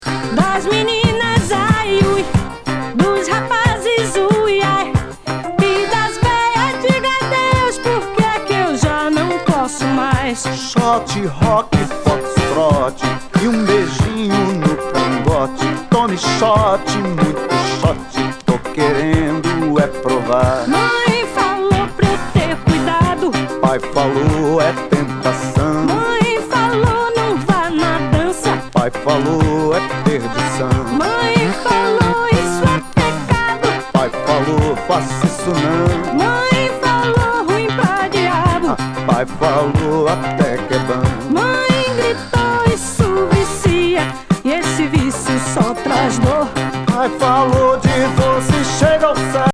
ディスコ・ファンク風